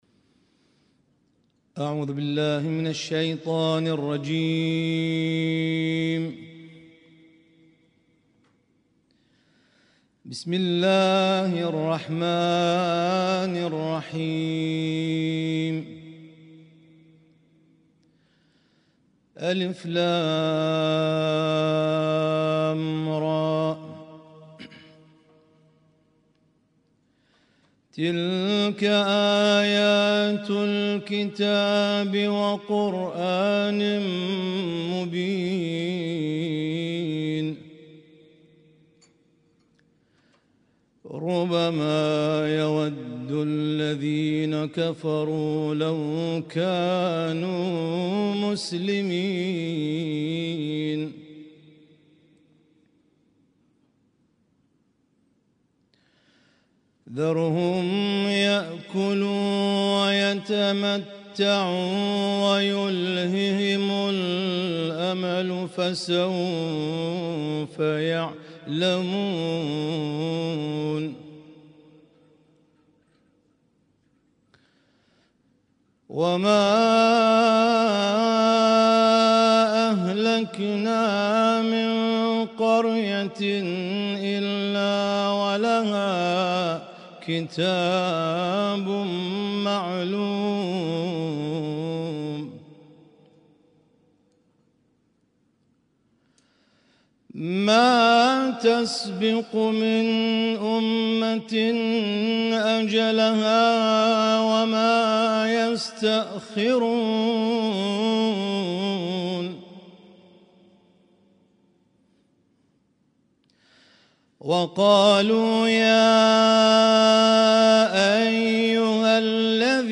ليلة 14 من شهر رمضان المبارك 1446هـ